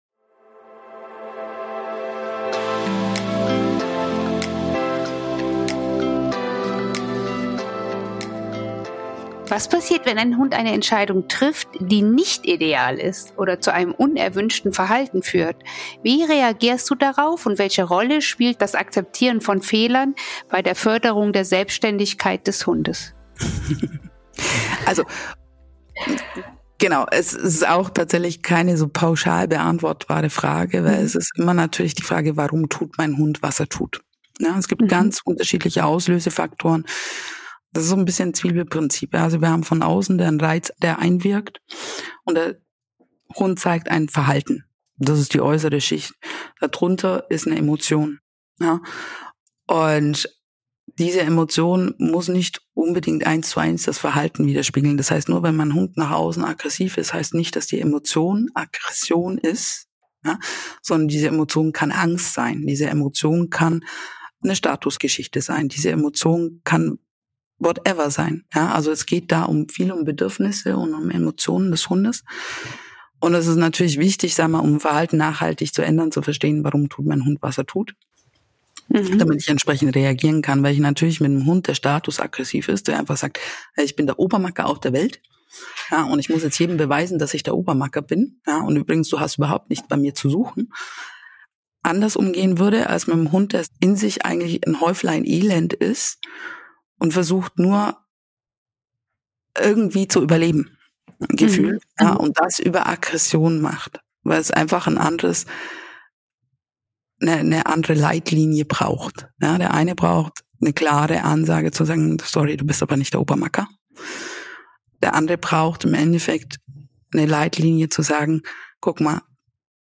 Verbindung von Mensch und Hund (Teil 4) – Interview